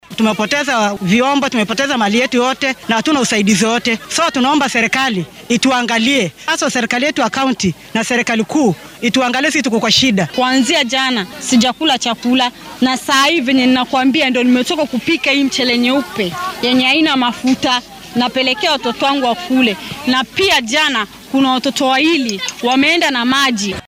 Qaar ka mid ah qoysaska ku barakacay dowlad deegaanka Tana River ayaa dareenkooda la wadaagay warbaahinta.
Barakacayaasha-Tana-River.mp3